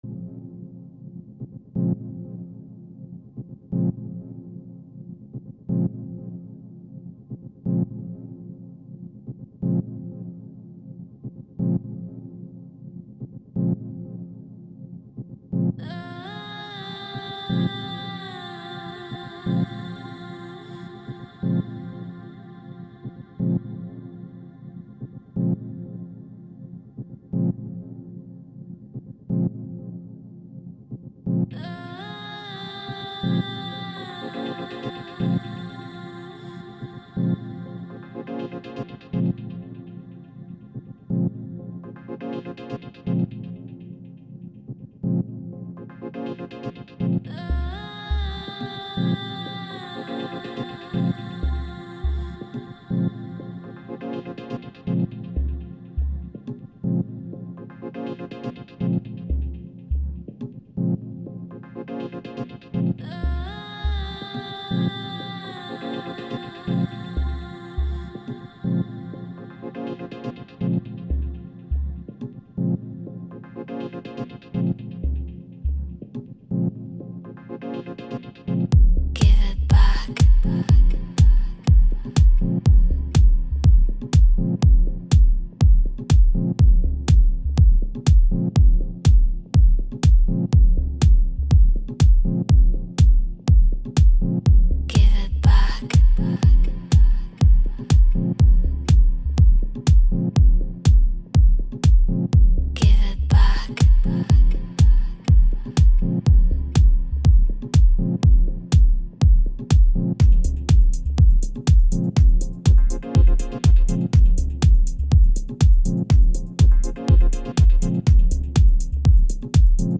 Deep Dub House